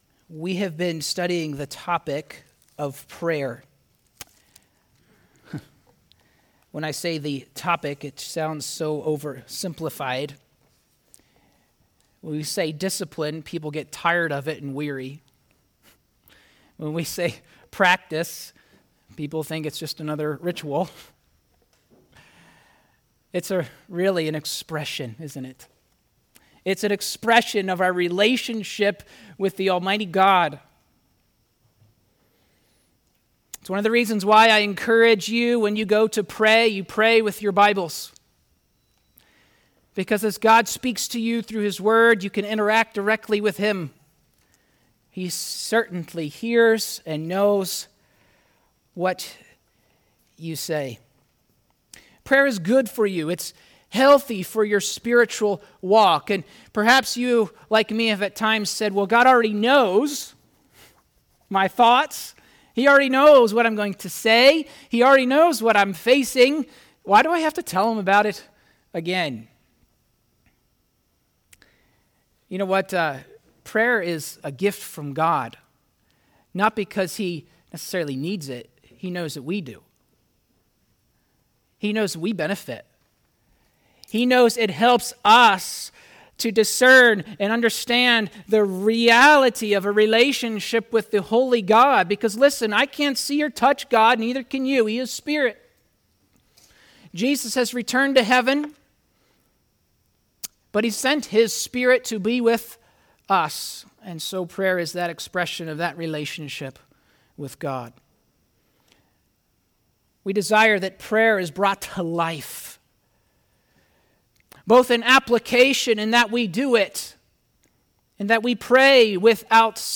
1 John 1:3-2:3 Service: Sunday Morning « Why Does Jesus Say “Don’t Tell Anyone”?